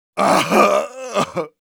Engineer_painsevere07_de.wav